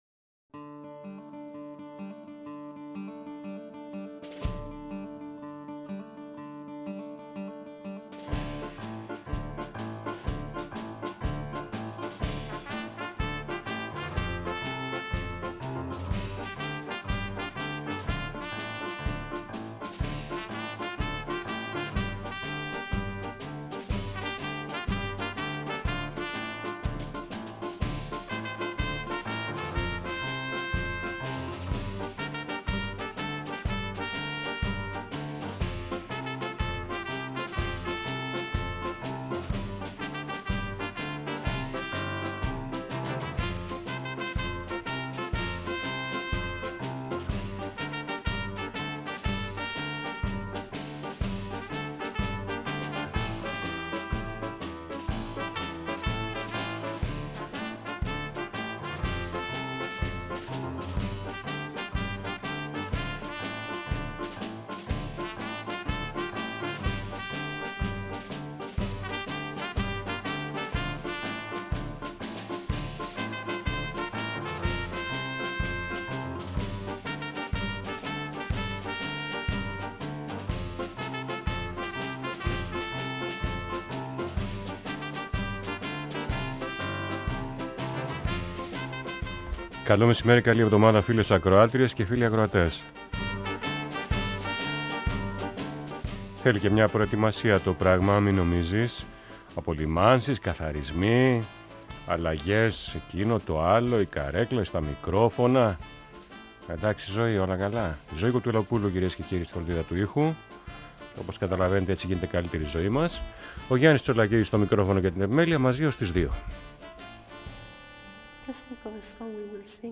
Μεσημεριανές ραδιοφωνικές ιστορίες για την Τέχνη, τον Πολιτισμό, την Ψυχαγωγία, τα μικρά και μεγάλα της καθημερινότητας.